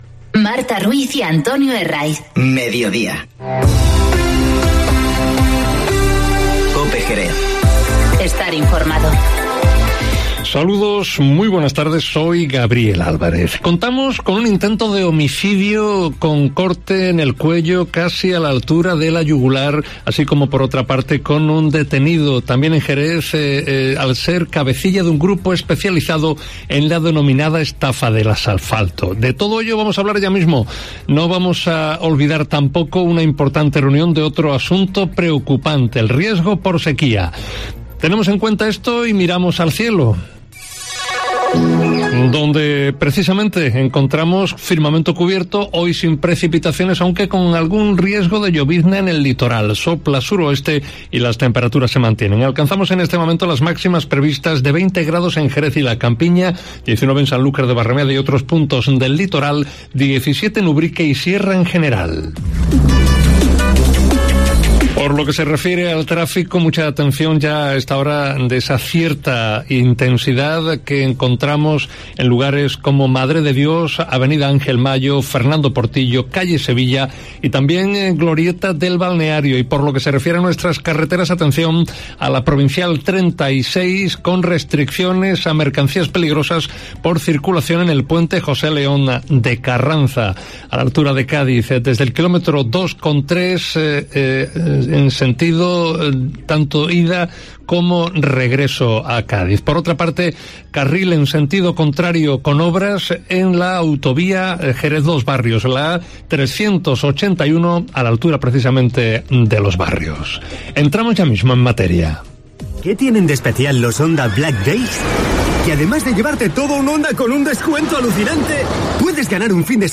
Informativo Mediodía COPE en Jerez 26-11-19